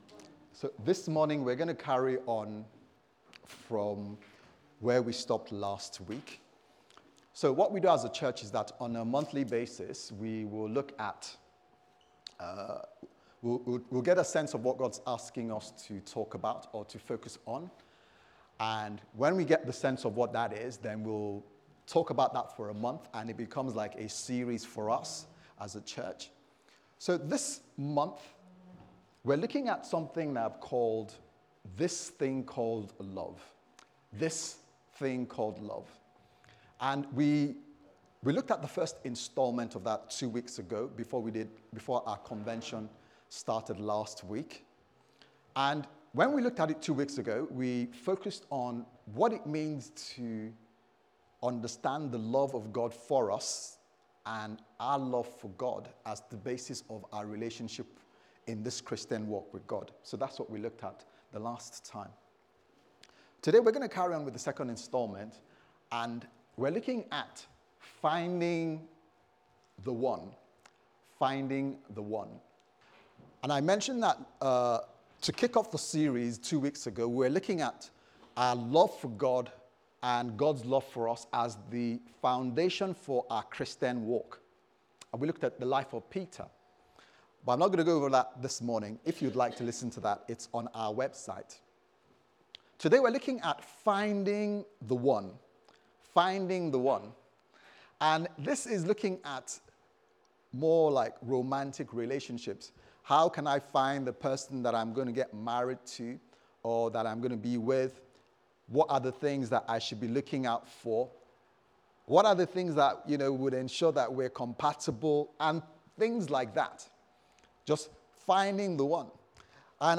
This Thing Called Love Service Type: Sunday Service Sermon « This Thing Called Love